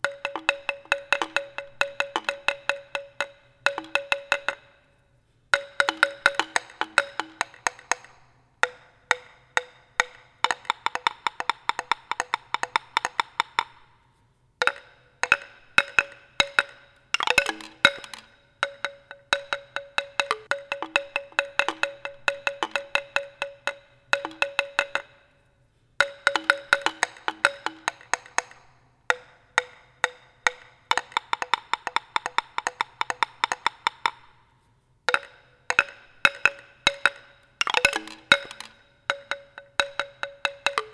Instrumento musical idiófono golpeado directamente. Está inspirado en una mezcla de objetos de percusión como la marimba, la tobera y txalaparta en la que se hace uso de varias teclas de diferentes tamaños que generan melodías al ser golpeados por baquetas.